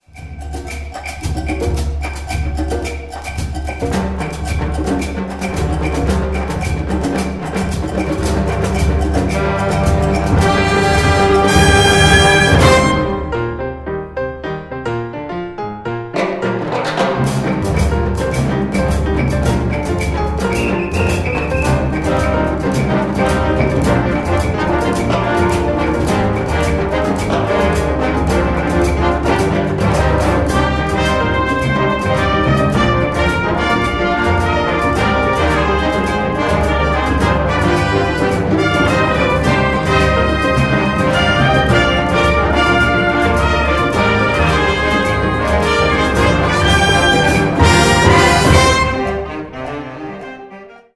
Gattung: Konzertante Blasmusik
Besetzung: Blasorchester